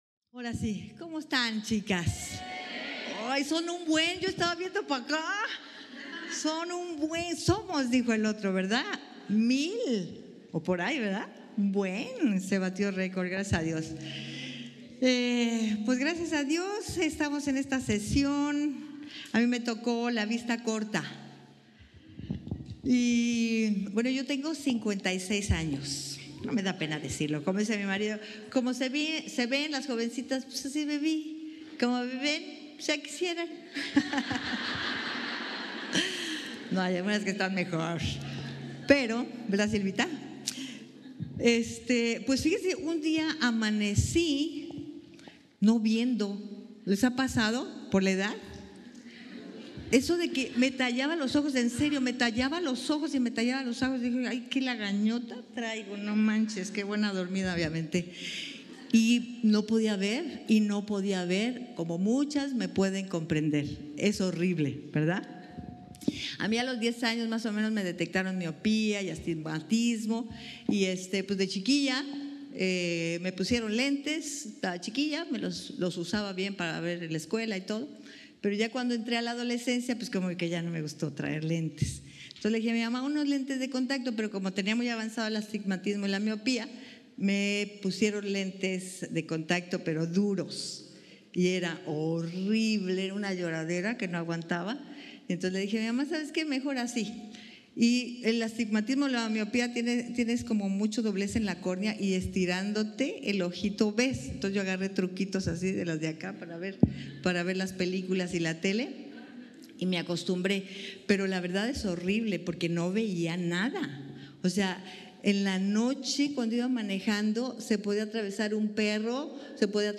Retiro 2018